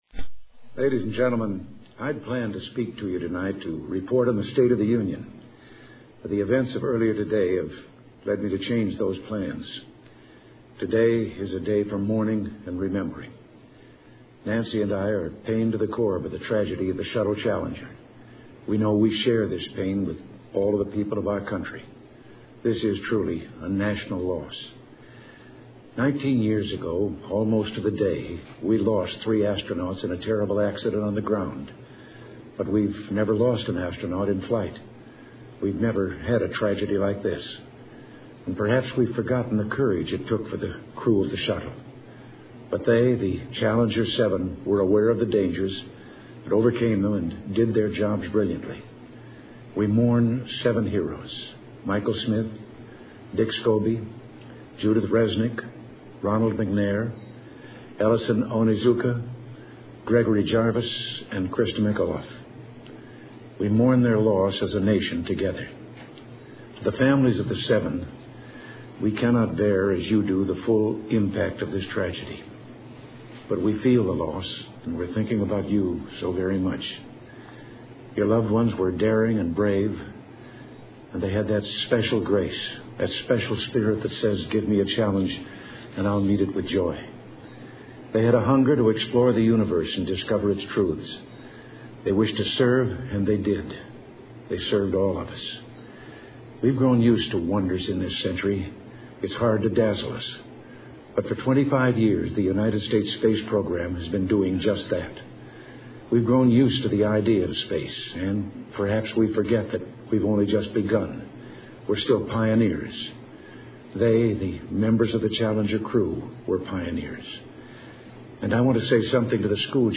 Ronald Reagan: The Space Shuttle "Challenger" Tragedy Address